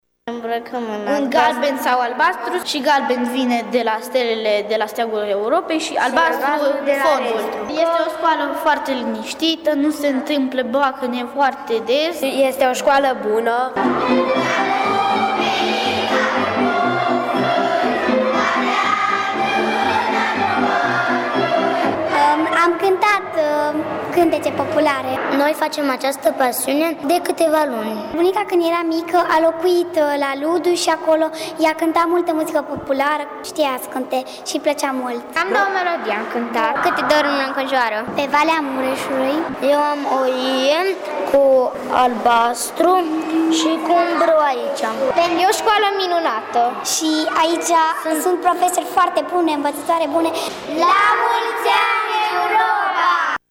Ziua Uniunii Europene a fost celebrată astăzi prin cântec, dans și mișcare de elevii și profesorii de la Școala Gimnazială Europa, din Tg.Mureș. Pe lângă momentele artistice create
Costumați în galben și albastru, culorile Europei, dar și în costume tradiționale, elevii au participat la un spectacol în care au recitat poezii, au cântat și au întins o horă a bucuriei pentru a celebra o Europă veselă și unită.